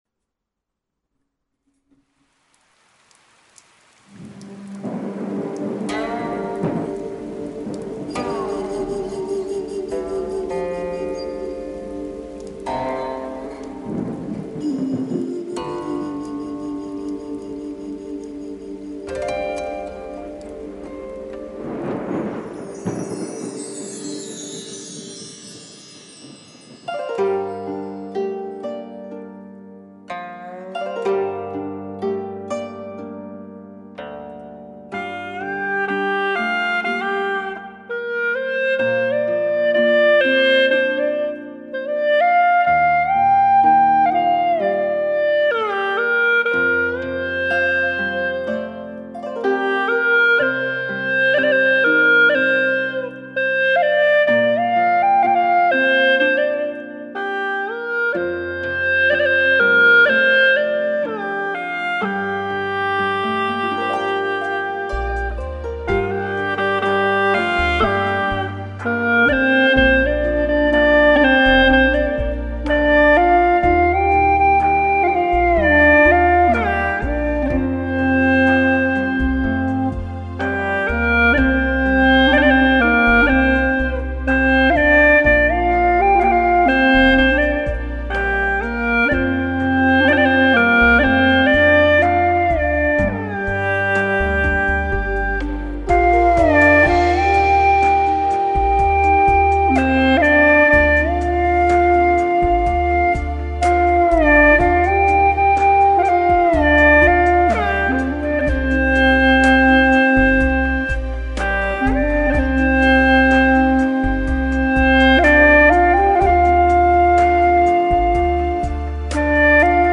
调式 : 降B
这曲特别适合晚上安安静静地听。。。。